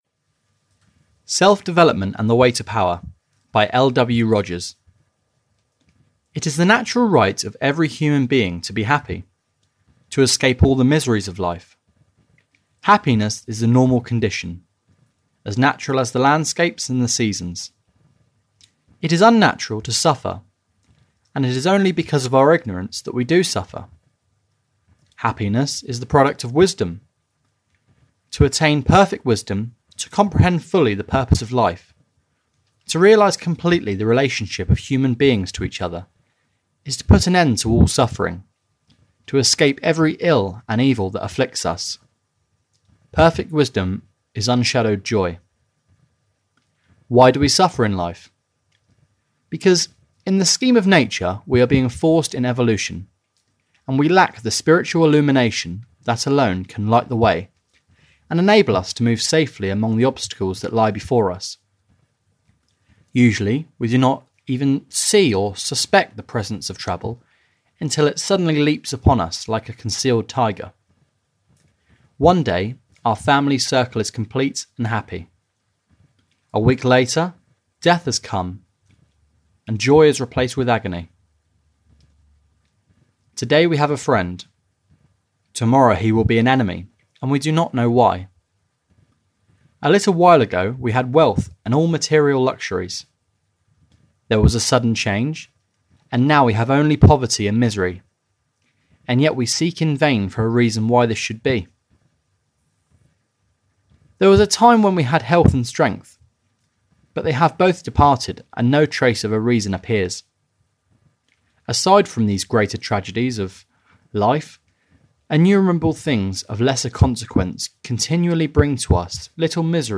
Audio knihaSelf Development And The Way to Power (EN)
Ukázka z knihy